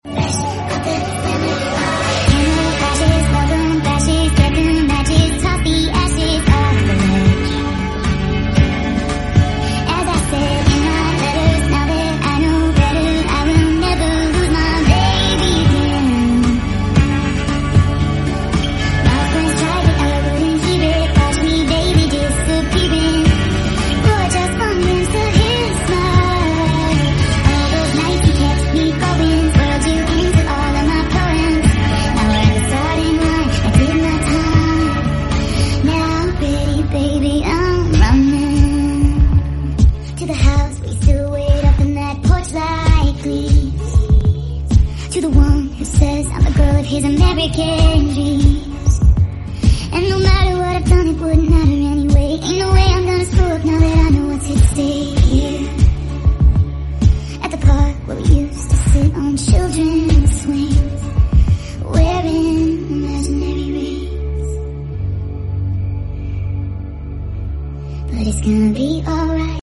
asmr morning in my life sound effects free download